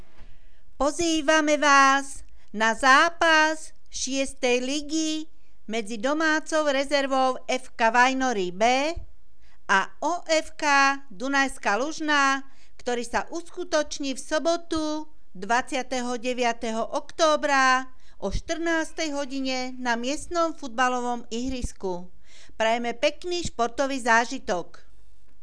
Hlásenie miestneho rozhlasu 28.10.2016 (FK Vajnory B a OFK Dunajská Lužná)